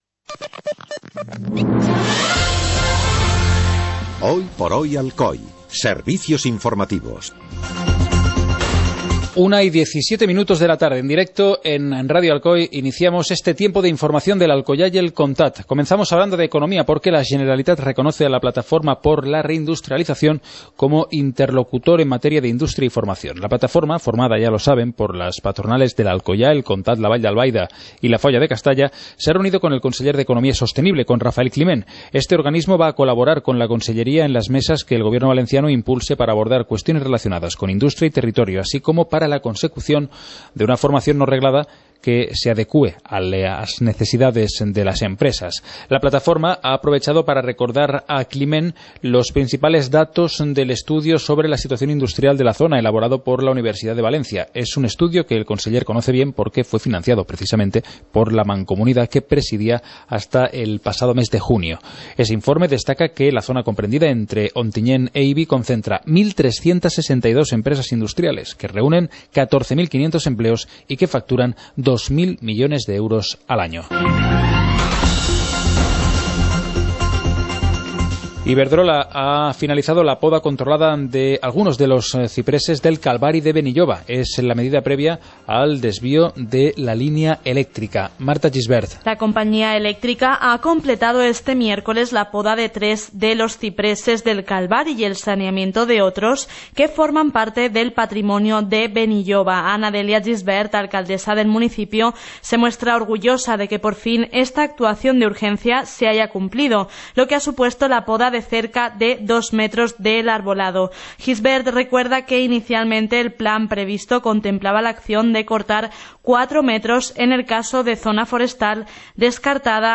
Informativo comarcal - jueves, 03 de septiembre de 2015